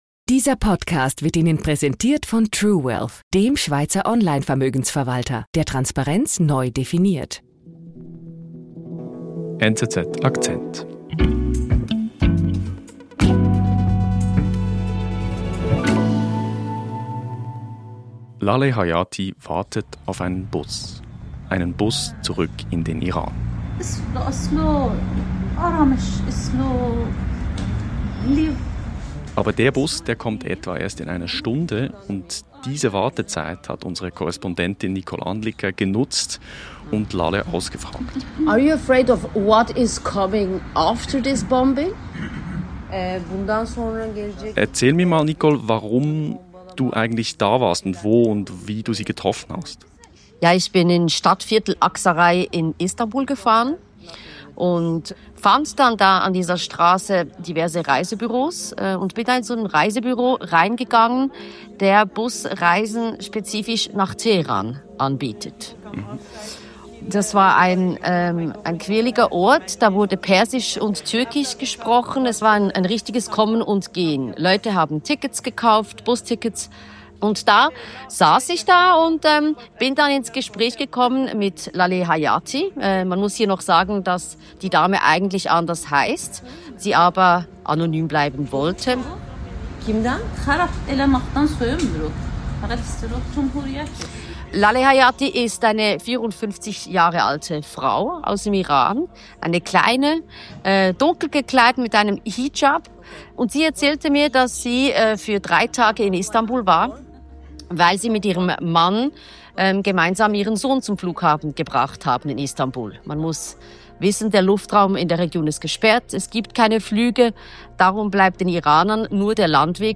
Diese Episode begleitet Iranerinnen und Iraner an einer Busstation in Istanbul, die trotz Bombardierungen die Heimreise antreten.